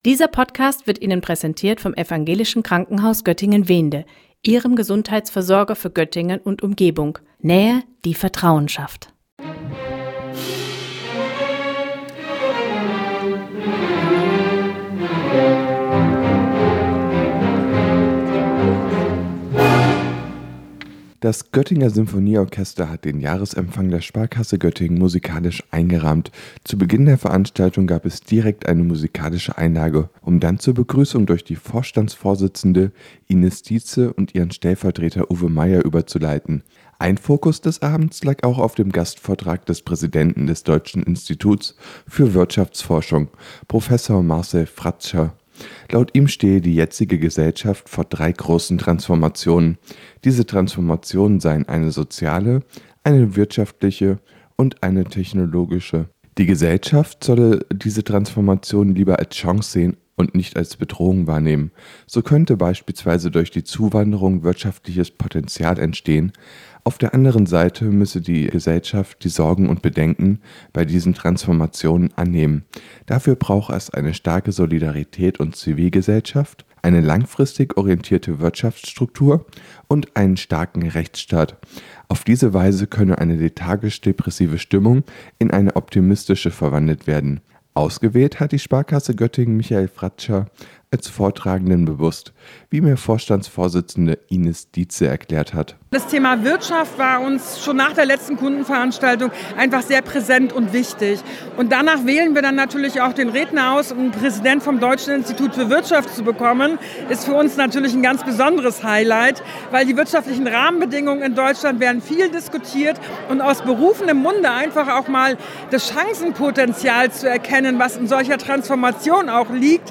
Bekannter Ökonom referiert beim Empfang der Sparkasse Göttingen 2:06
Rot und weiß leuchtete die Decke in der Stadthalle Göttingen am Donnerstagabend. Grund hierfür war der Jahresempfang der Sparkasse Göttingen, bei dem auch das StadtRadio vor Ort war.